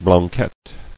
[blan·quette]